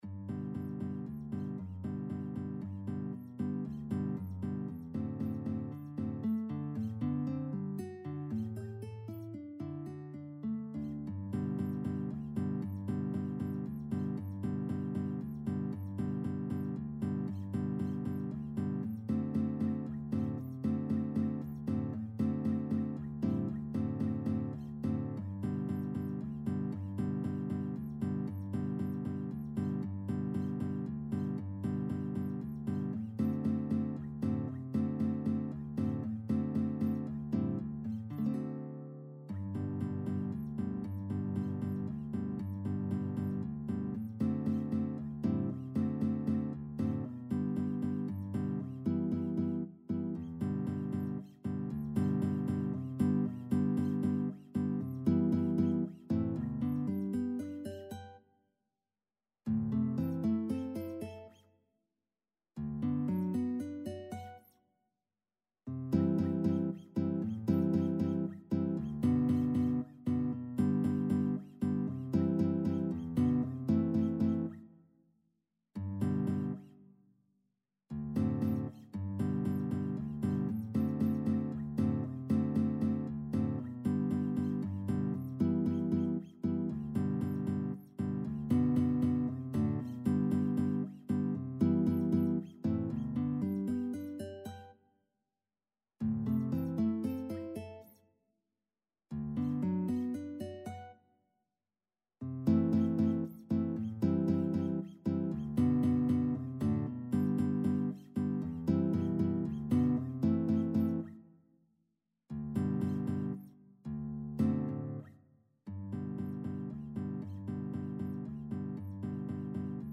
3/4 (View more 3/4 Music)
Allegretto =116 Allegretto =120
Classical (View more Classical Violin-Guitar Duet Music)